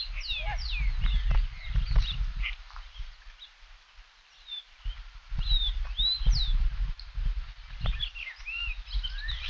Ash-throated Crake (Mustelirallus albicollis)
Location or protected area: Parque Nacional Iberá
Condition: Wild
Certainty: Observed, Recorded vocal